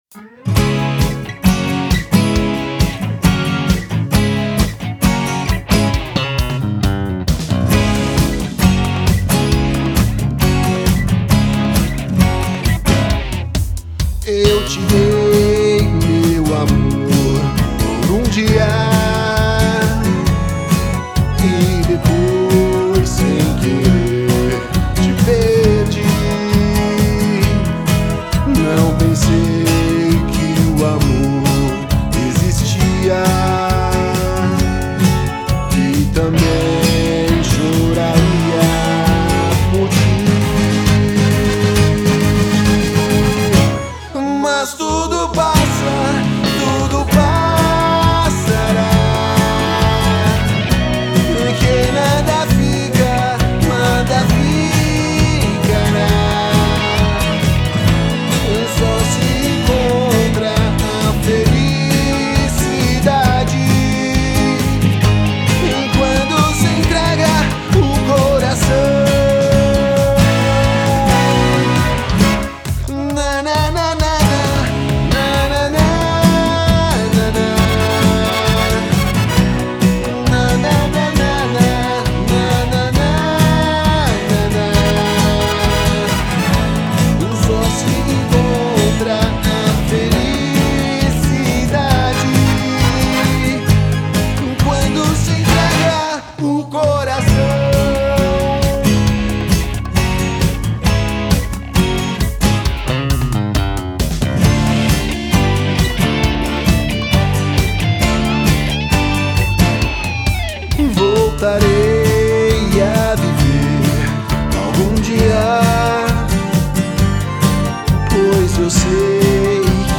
pop rock inovado com acordeon argentino